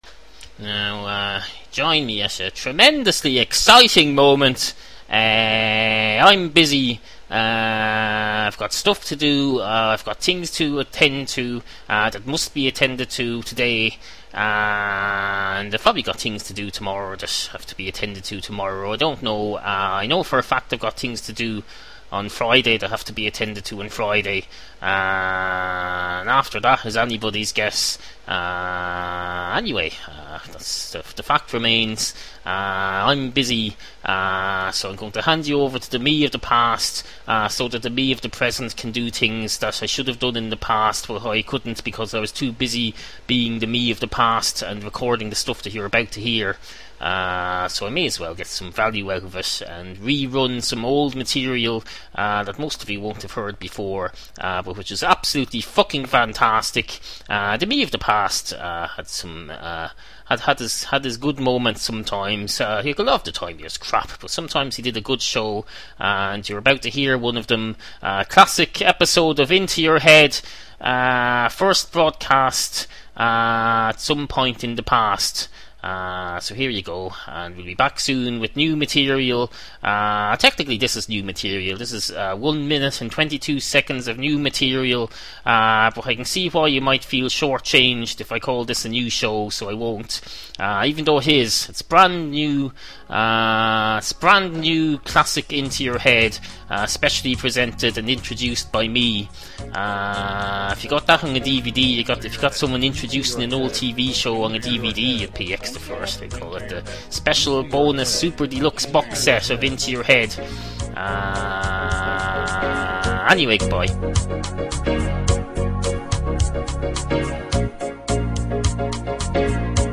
Obscure 21st Century Irish audio comedy series